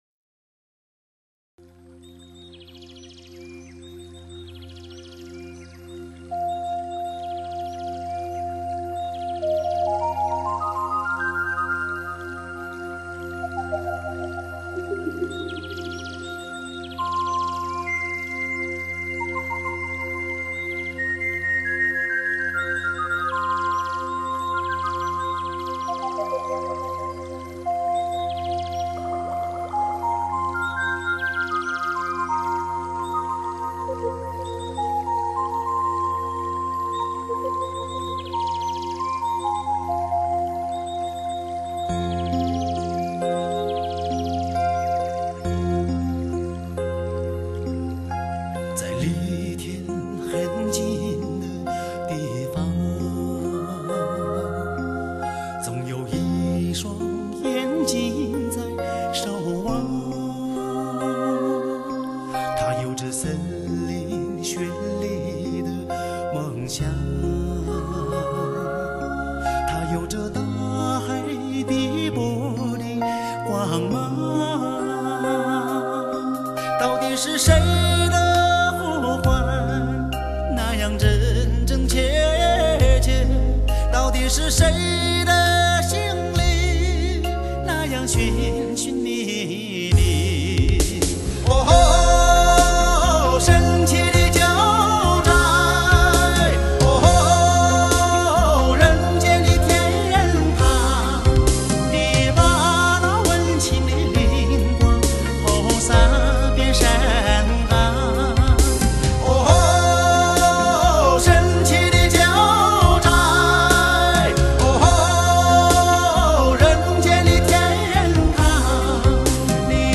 流行男声